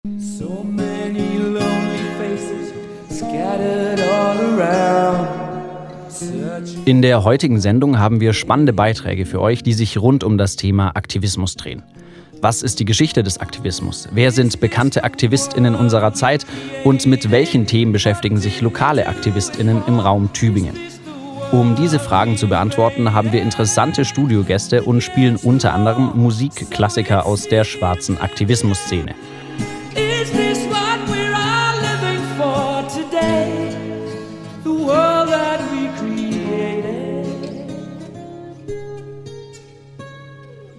Teaser_527.mp3